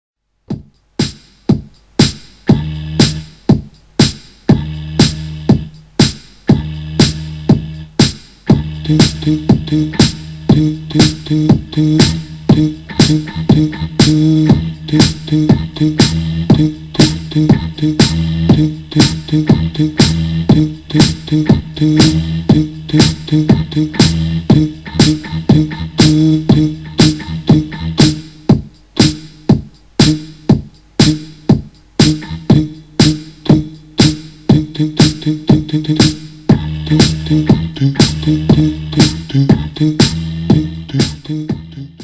C синтезатором =)
во второй записи я вообще битбокс не слышал. выложи нормальную запись